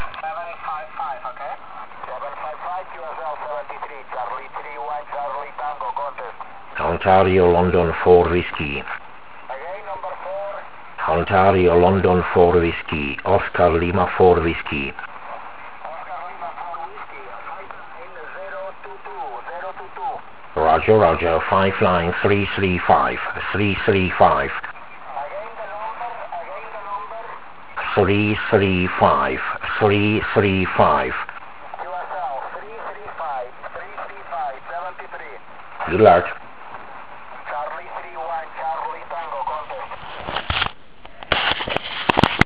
(V dalším jsou záznamy z tohoto závodu)